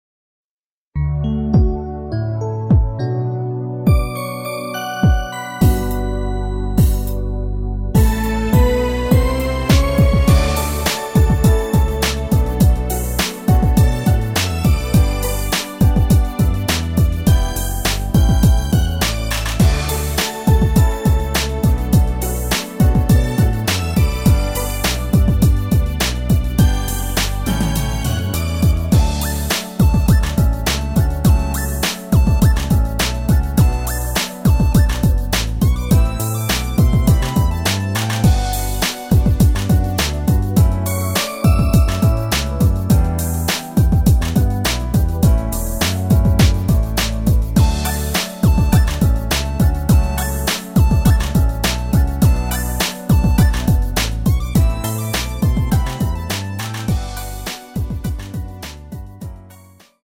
멜로디 MR이라고 합니다.
앞부분30초, 뒷부분30초씩 편집해서 올려 드리고 있습니다.
중간에 음이 끈어지고 다시 나오는 이유는